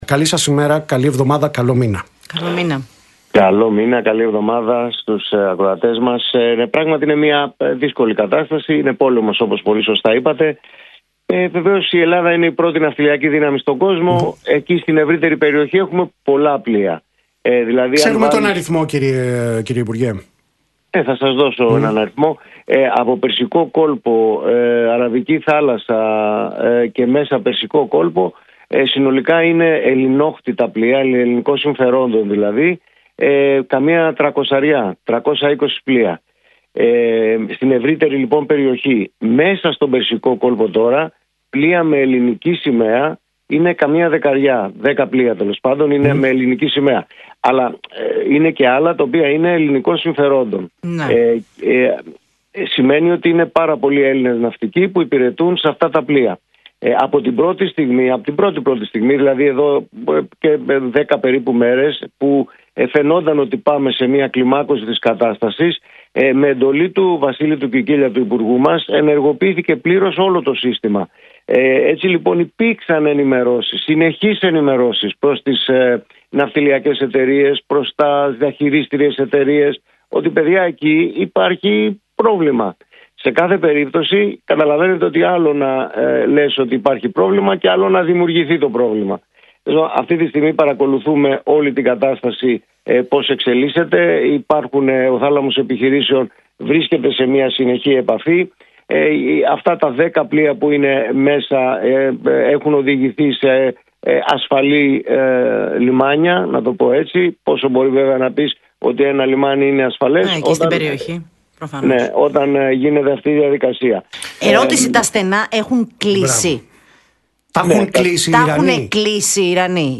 «Είναι δύσκολη κατάσταση, είναι πόλεμος» τόνισε χαρακτηριστικά ο υφυπουργός Ναυτιλίας και πρόσθεσε: